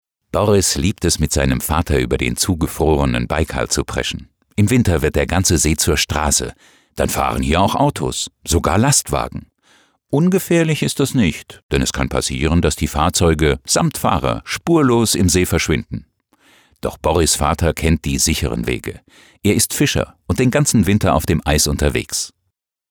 Deutscher Sprecher.
Sprechprobe: Sonstiges (Muttersprache):
german voice over artist